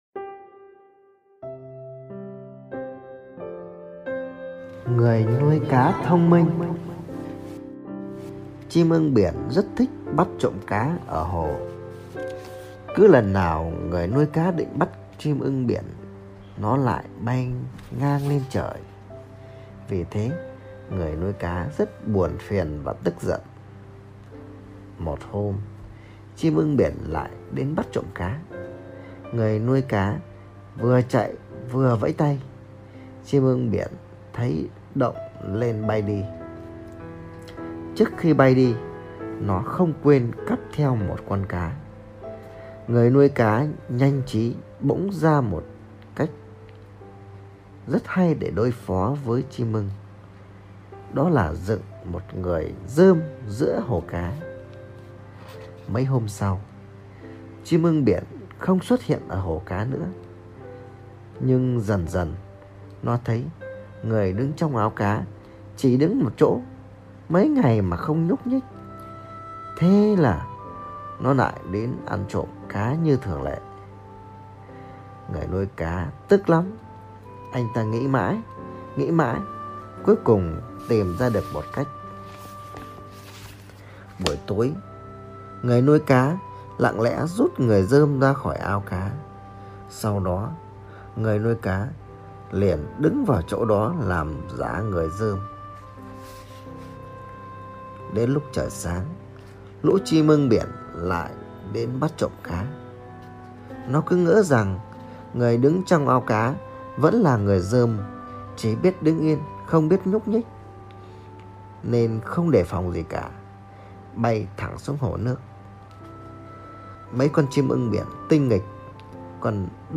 Sách nói | Con nuôi cá thông minh